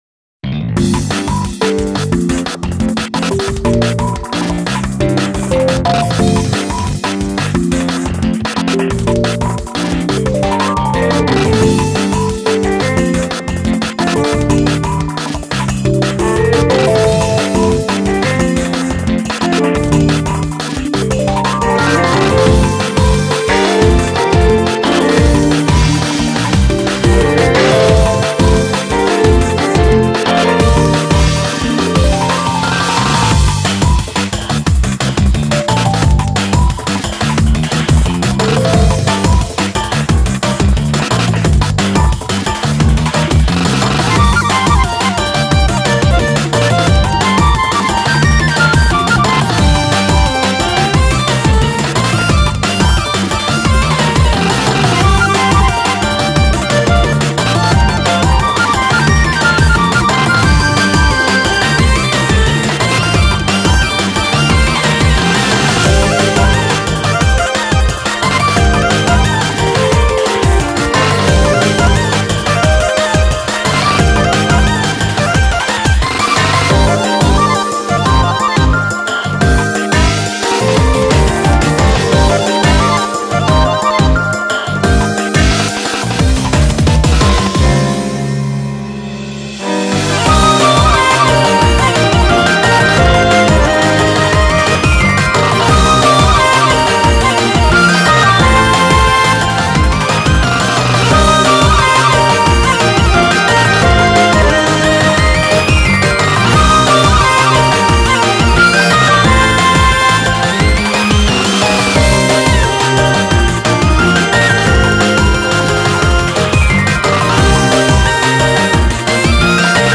BPM177
Audio QualityPerfect (High Quality)
Comments[ASIAN JANGLE]